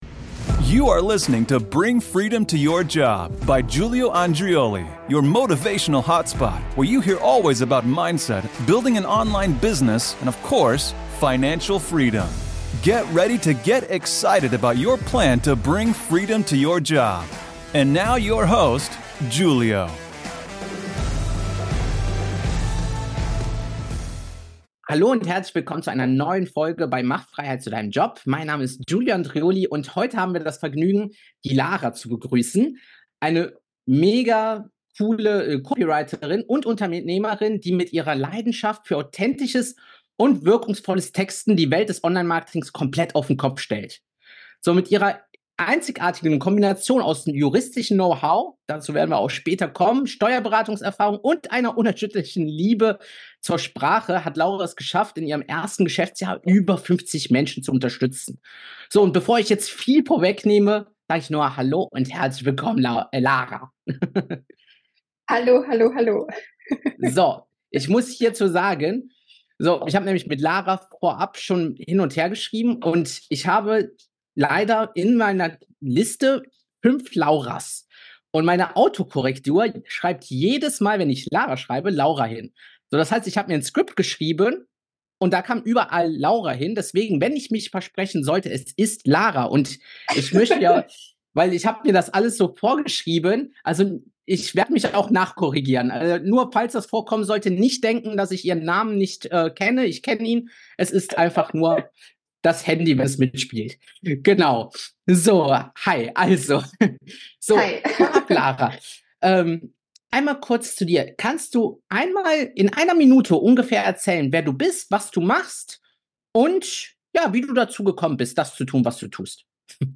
Dieses Interview ist ein Muss für dich, wenn du lernen willst, wie du mit authentischen und wirkungsvollen Texten dein Business voranbringst.